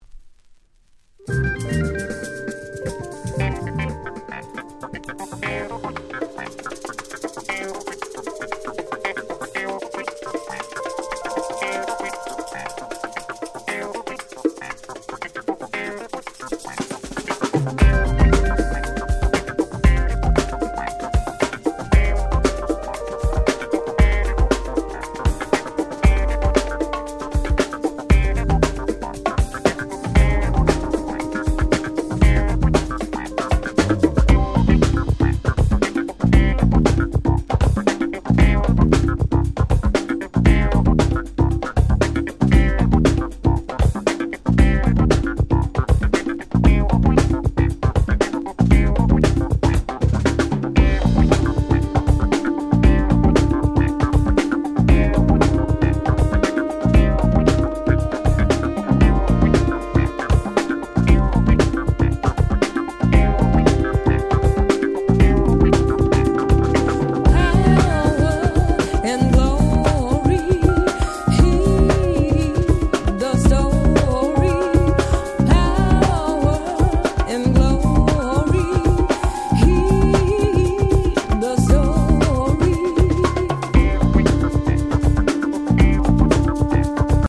90's Acid Jazz Classics !!
オフィシャルリリースなので音質もバッチリ！！